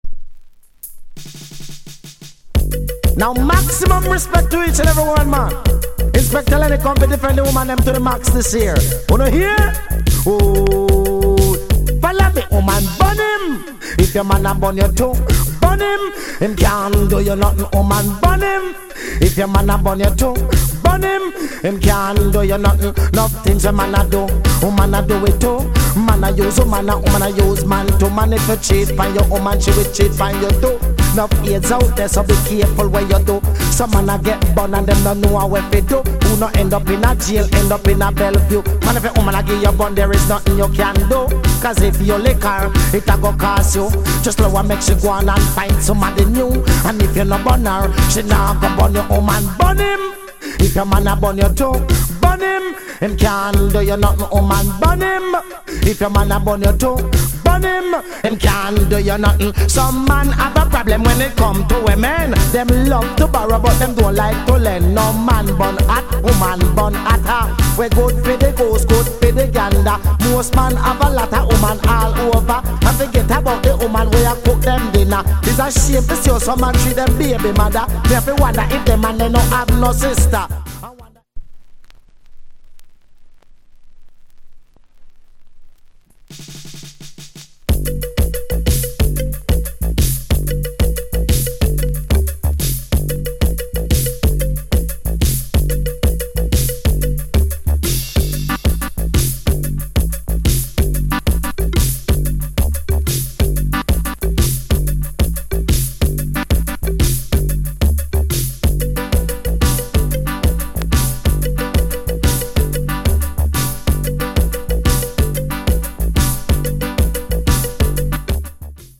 Production Genre ReggaeAfter90s / Male DJ